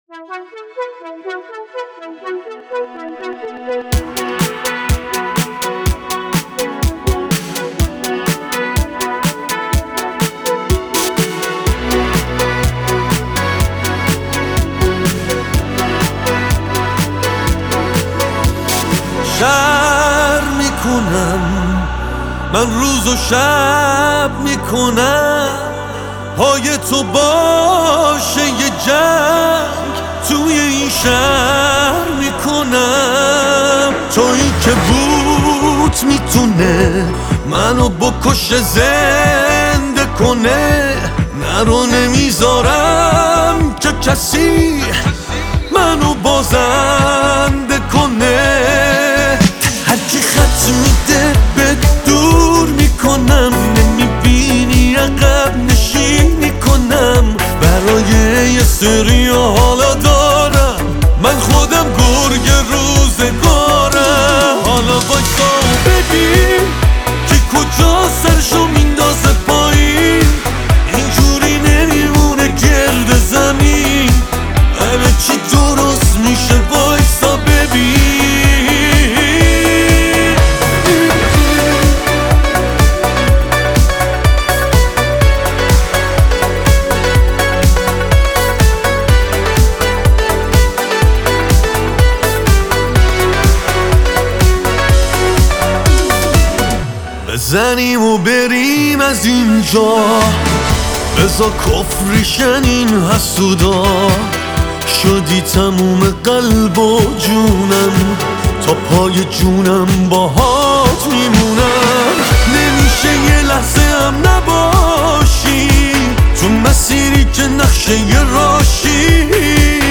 Enjoy the romantic track with deep bass in high-quality MP3.
Genre: Pop